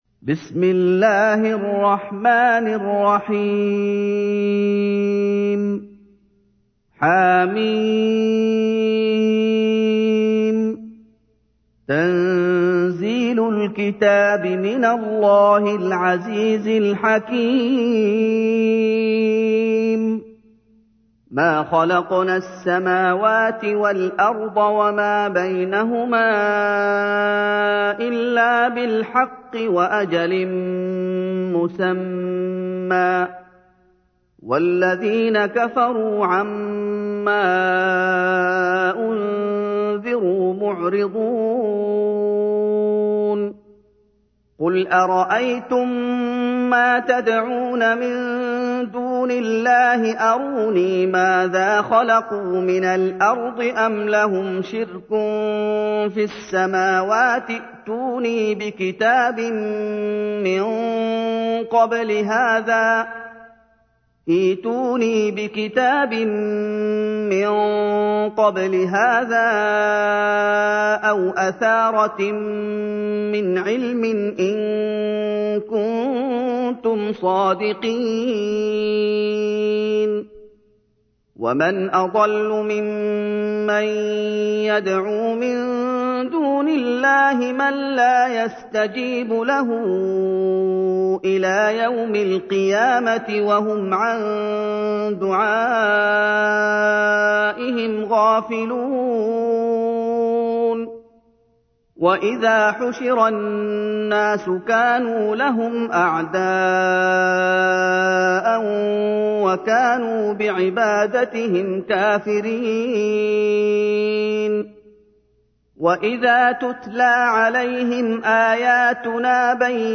تحميل : 46. سورة الأحقاف / القارئ محمد أيوب / القرآن الكريم / موقع يا حسين